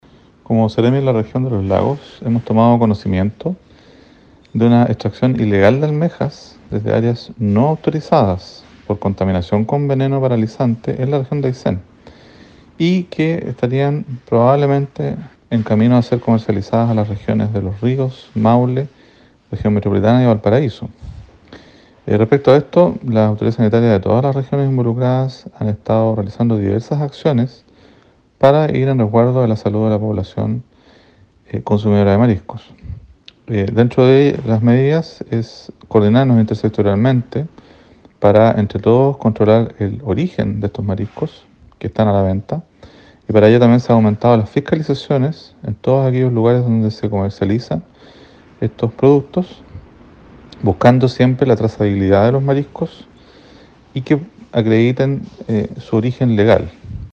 Por ello el seremi Carlos Becerra llamó a la ciudadanía a extraer mariscos sólo en áreas permitidas y comprar siempre en locales autorizados.